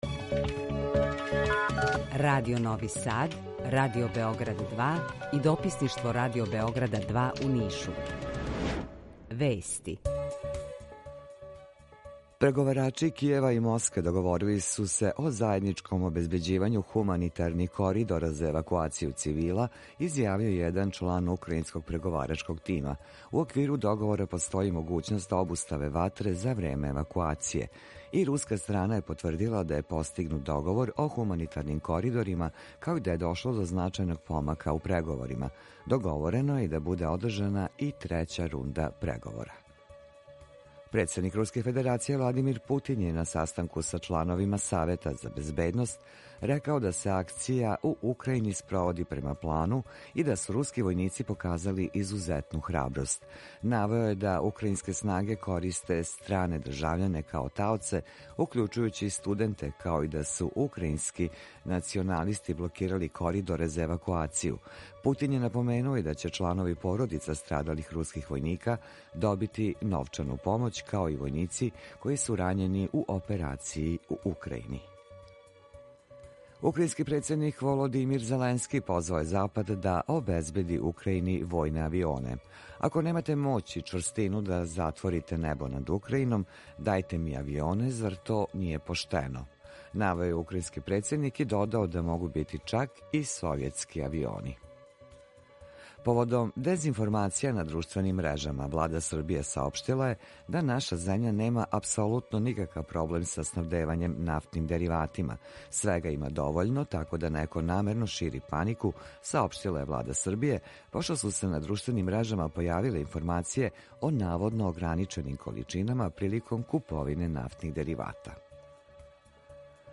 Емисију реализујемо заједно са студијом Радија Републике Српске у Бањалуци и Радијом Нови Сад
У два сата, ту је и добра музика, другачија у односу на остале радио-станице.